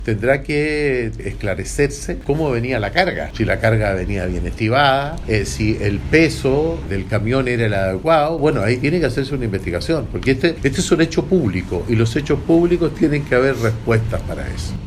El senador de la UDI, Iván Moreira, expresó que espera que la investigación de cuenta de los responsables del hecho.